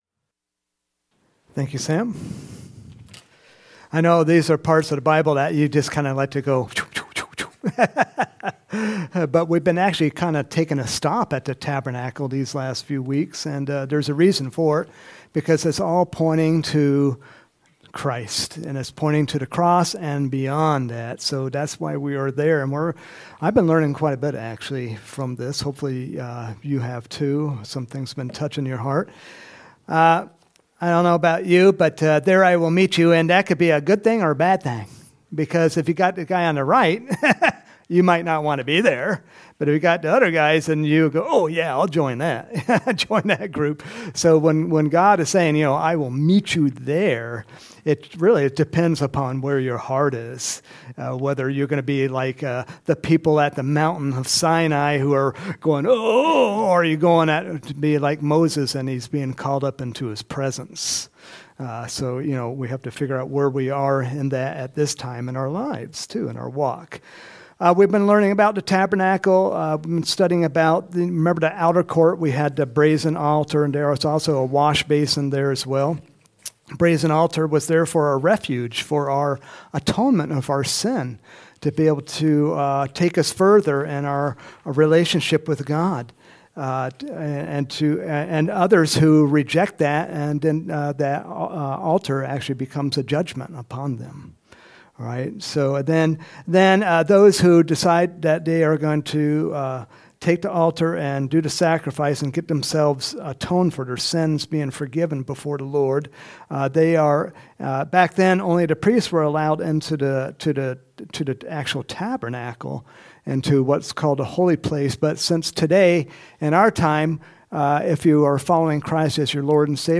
Home › Sermons › March 24, 2024